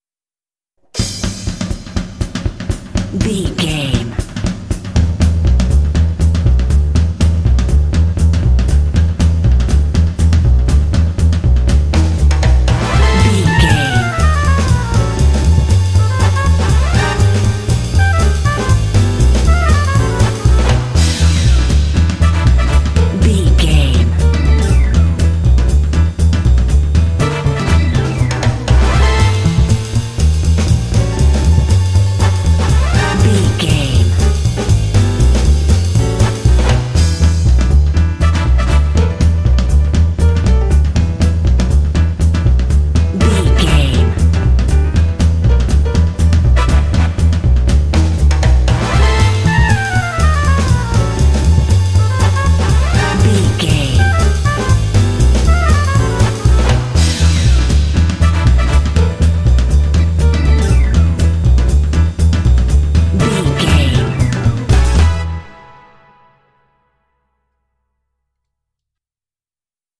Uplifting
Aeolian/Minor
Fast
driving
energetic
lively
cheerful/happy
drums
double bass
piano
electric organ
brass
big band
jazz